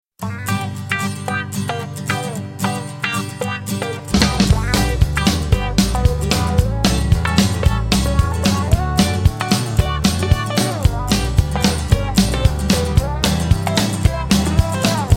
MP3 Demo Instrumental Version